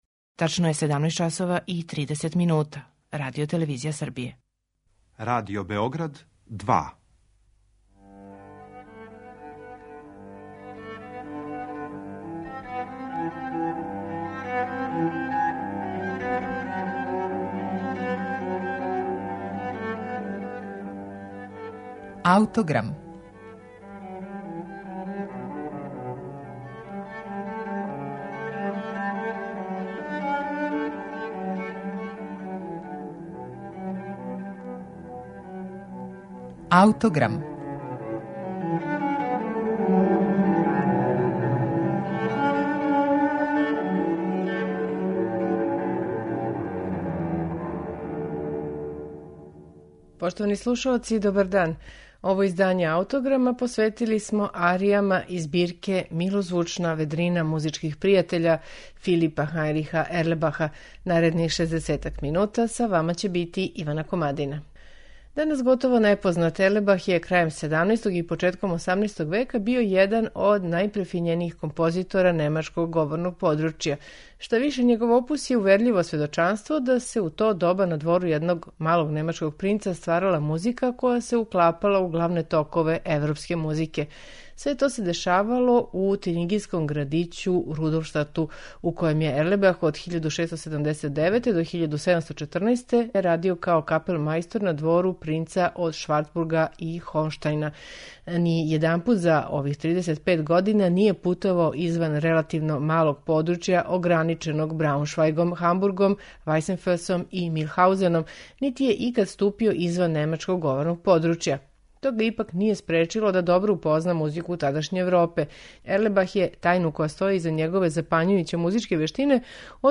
баритон и ансамбл Стилус фантастикус